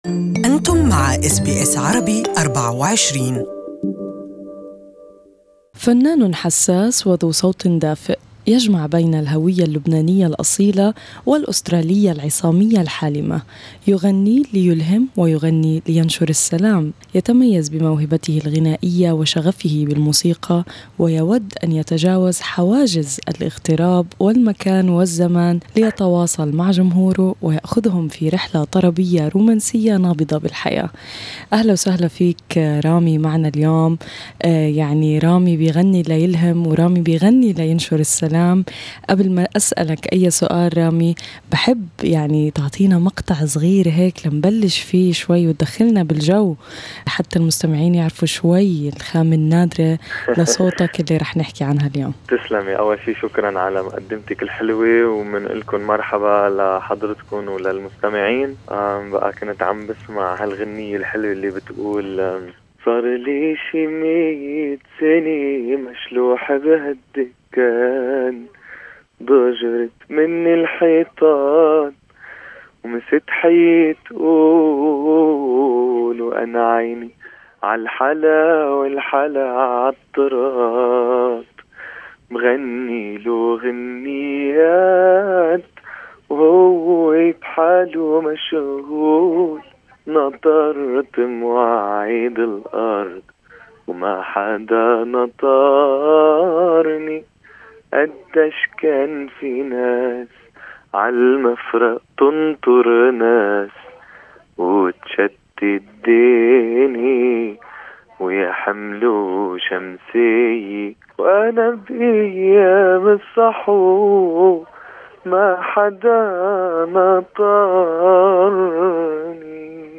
المقابلة الصوتية